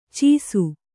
♪ cīsu